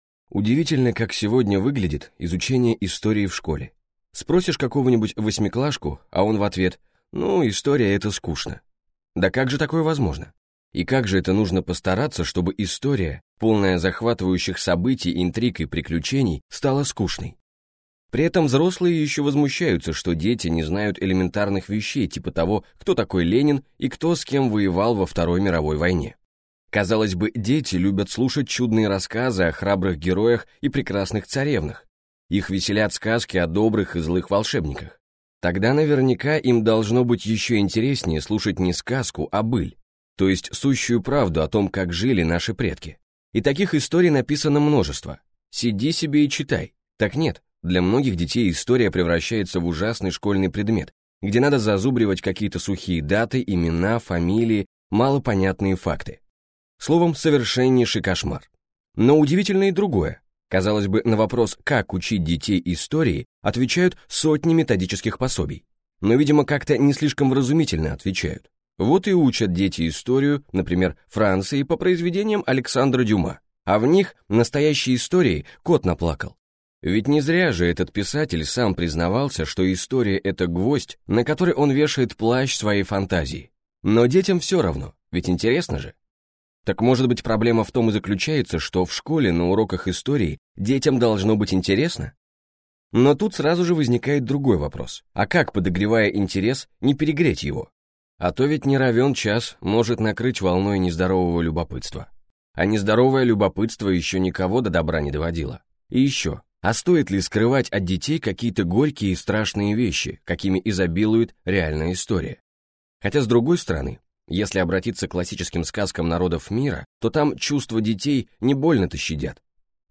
Аудиокнига Русская история на пальцах. Для детей и родителей, которые хотят объяснять детям | Библиотека аудиокниг